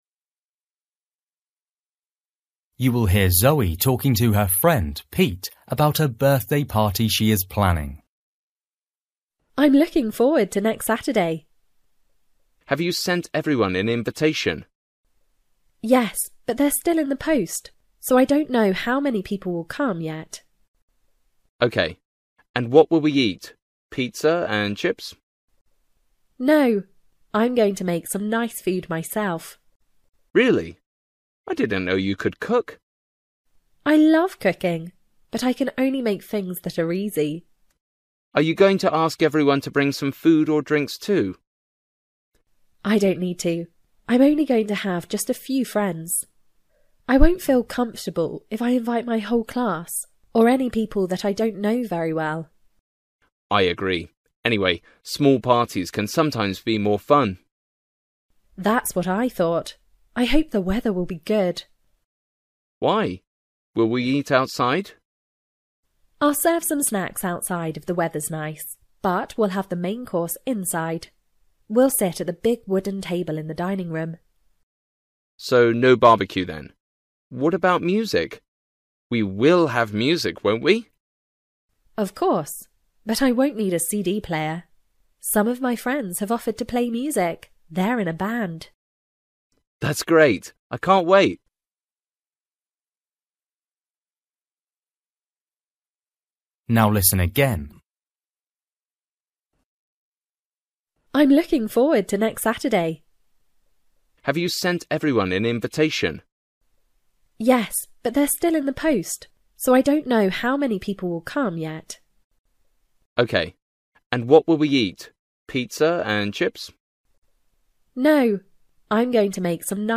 Bài tập trắc nghiệm luyện nghe tiếng Anh trình độ sơ trung cấp – Nghe một cuộc trò chuyện dài phần 20